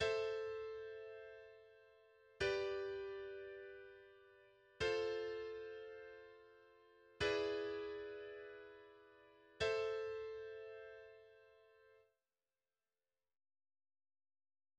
Типичная Андалузская каденция por arriba (в ля миноре).